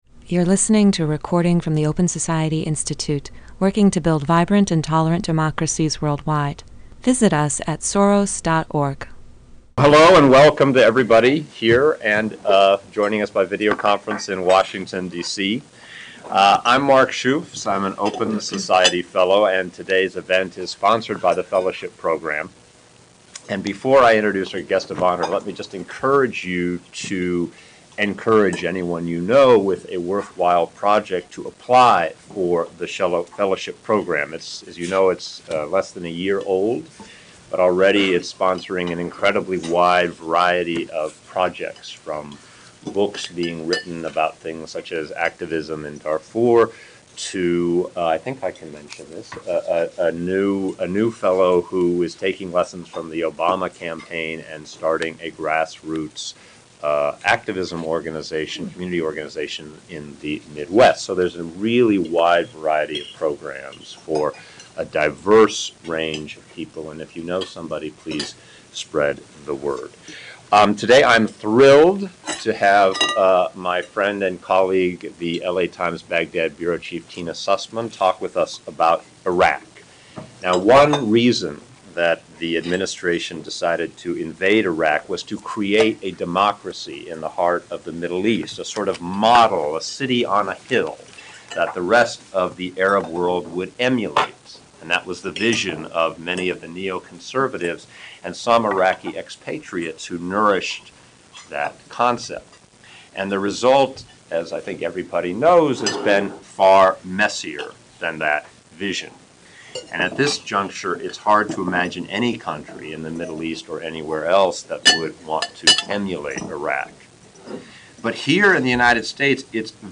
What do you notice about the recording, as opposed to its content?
Debaters from eight universities and six countries put on a public exhibition debate in "World Style," the most popular format of debate. Note: The audio for this event has been edited.